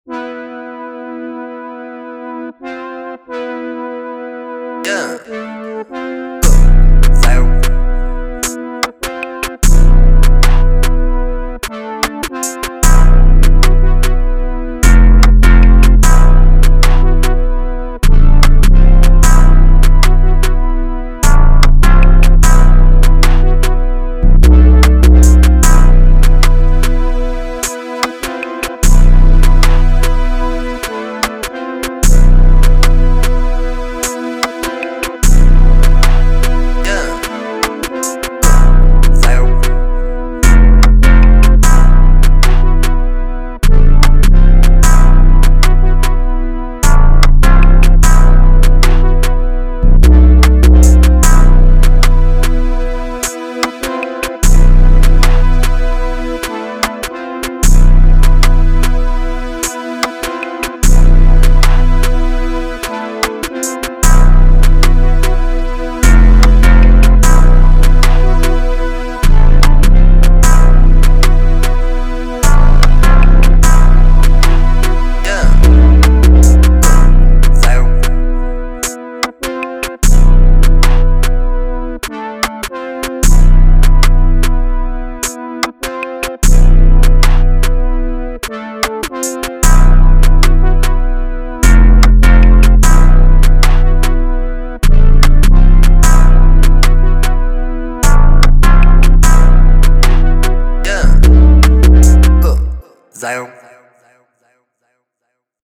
Hard
150 B Minor